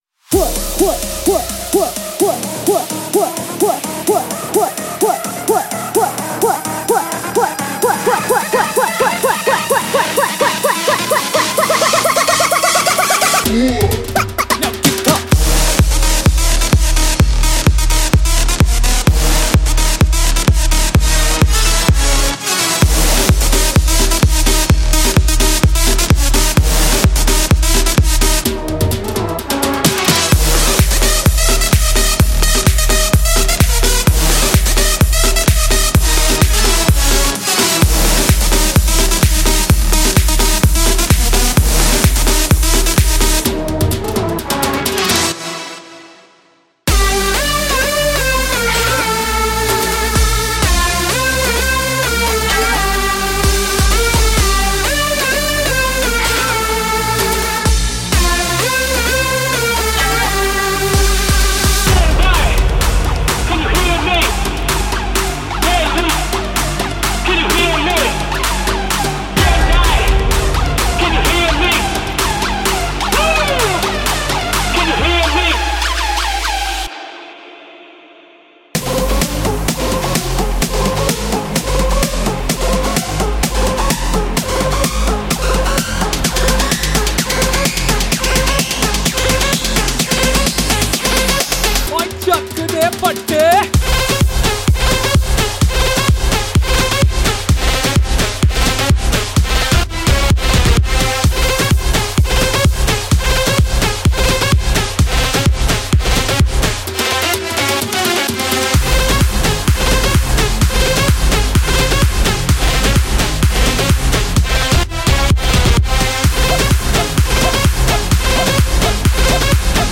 .50个Bansuri循环
·10个Sitar循环
.35个Dhol循环
.20个Tabla循环